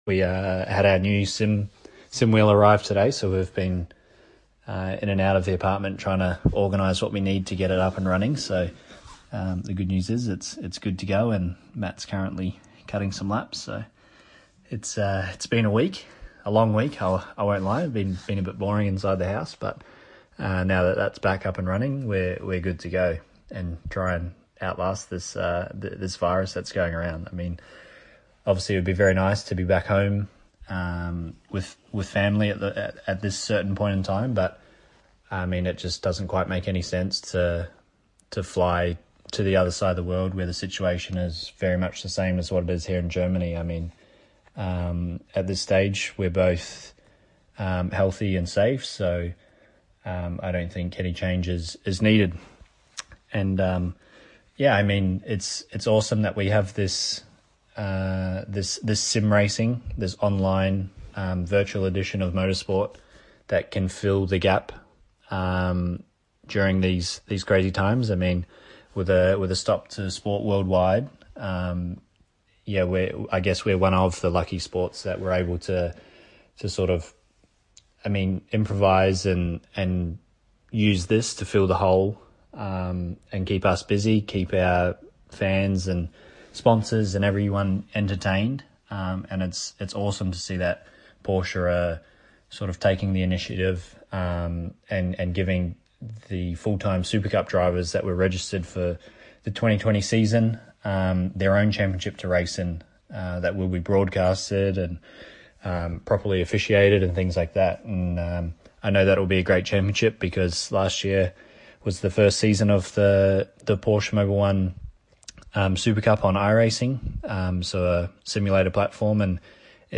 Evan’s sent us a through a great voice message to share.